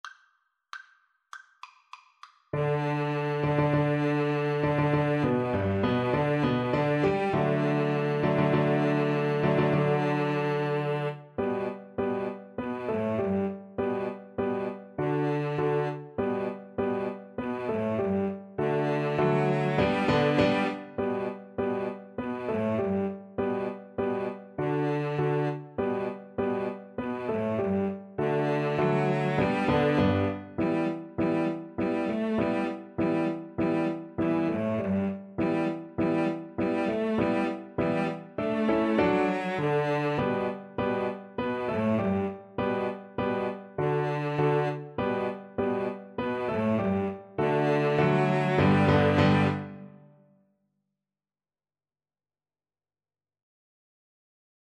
G major (Sounding Pitch) (View more G major Music for Violin-Cello Duet )
4/4 (View more 4/4 Music)
Presto =200 (View more music marked Presto)
Violin-Cello Duet  (View more Easy Violin-Cello Duet Music)
Classical (View more Classical Violin-Cello Duet Music)